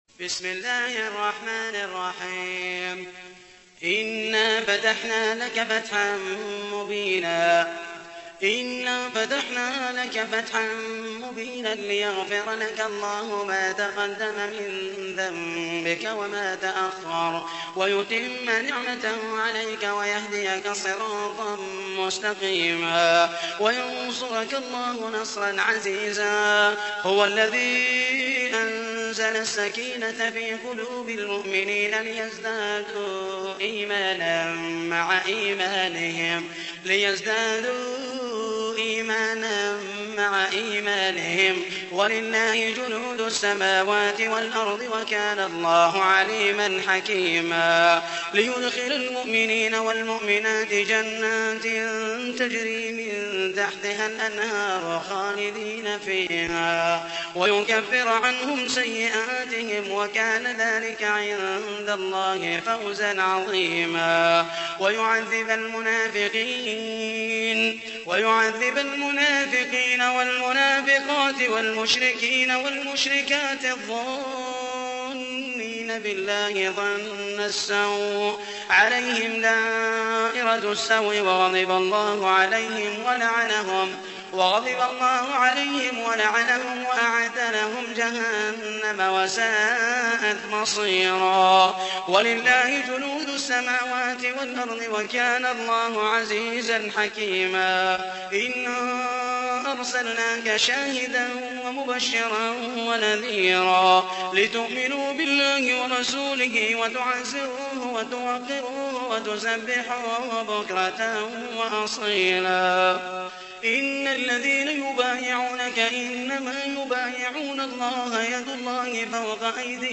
تحميل : 48. سورة الفتح / القارئ محمد المحيسني / القرآن الكريم / موقع يا حسين